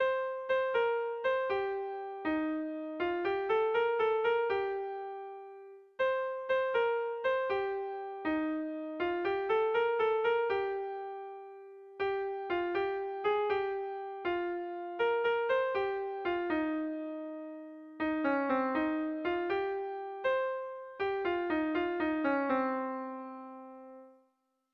Erlijiozkoa
Doinu hau alemana omen da.
AAA